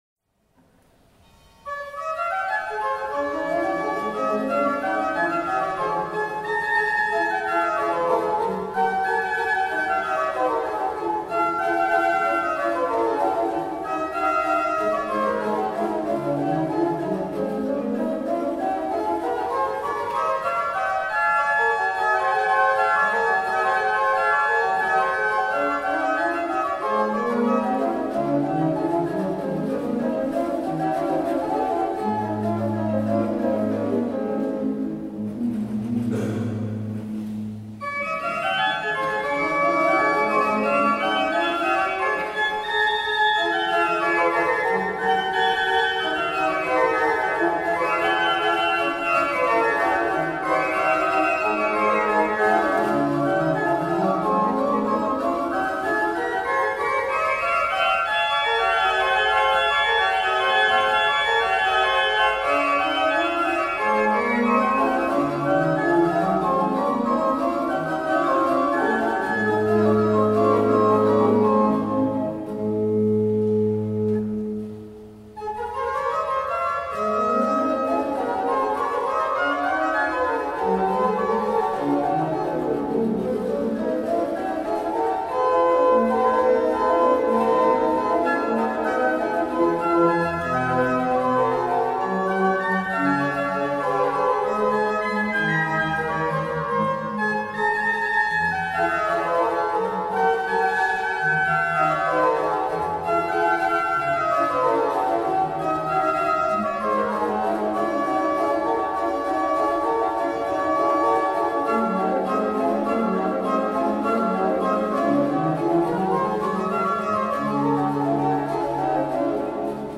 Rezzato (BS), Ottobre 2013
Dal Cd Ostinate Variazioni - Organo Giuseppe BONATTI, 1713 - Rezzato (BS)